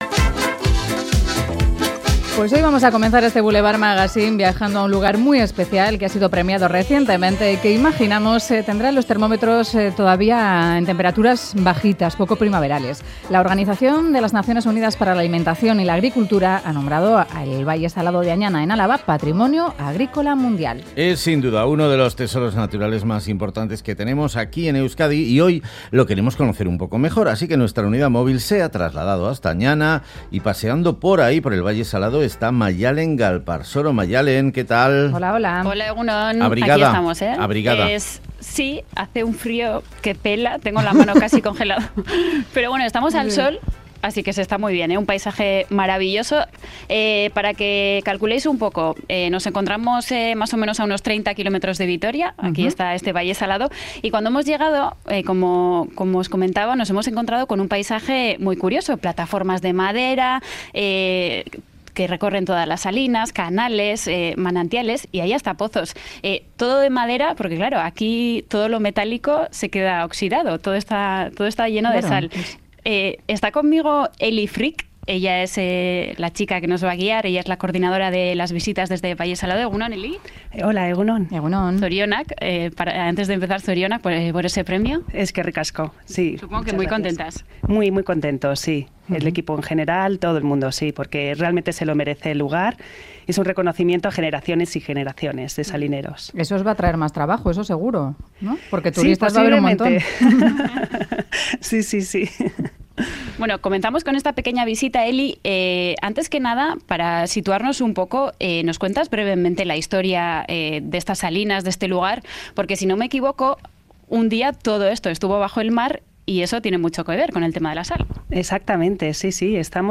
Audio: 'Boulevard' se ha desplazado hasta el Valle Salado de Añana, elegido como Patrimonio Agrícola Mundial por la Organización de las Naciones Unidas para la Alimentación y la Agricultura.